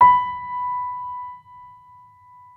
piano-sounds-dev
Vintage_Upright
b4.mp3